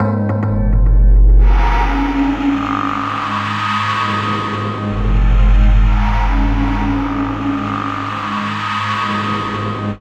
Index of /90_sSampleCDs/Chillout (ambient1&2)/13 Mystery (atmo pads)
Amb1n2_x_pad_g#.wav